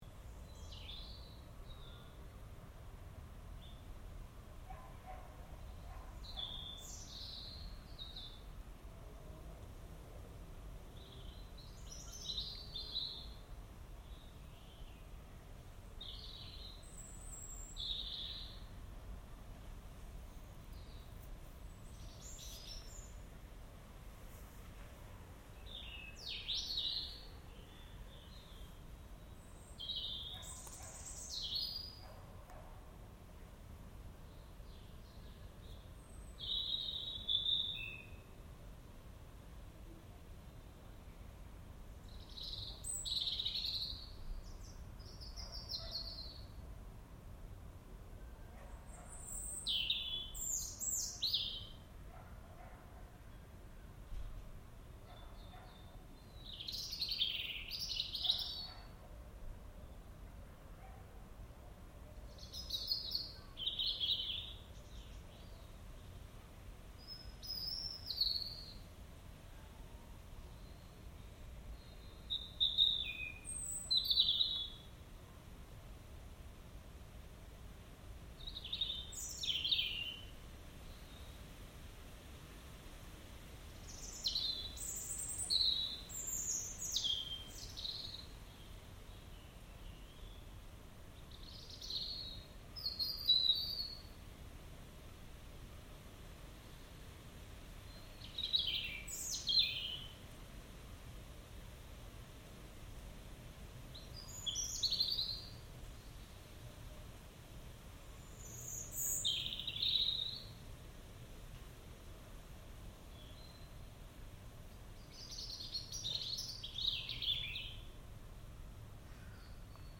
Wednesday Evening Robin in the Garden
Beautiful birdsong from a robin in my garden, with a neighbour's dog and other sounds in the background. This recording, made on 9 June 2021, is binaural, so best listened to with good speakers or, preferably, headphones.